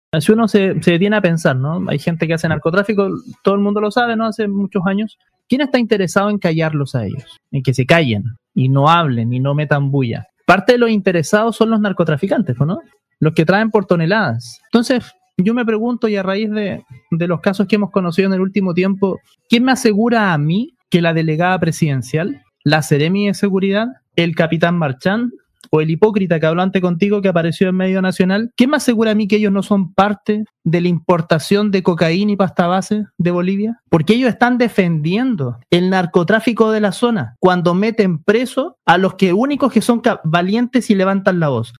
Los dichos del edil fueron realizados el jueves en el programa Hoy X Hoy, cuando el consejero abordaba la situación de violencia y las balaceras ocurridas durante la última semana en el centro de Iquique, además las detenciones de vecinos que protestaron por estos hechos y la falta de presencia policial en la zona.